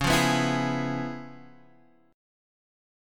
Db7#9 chord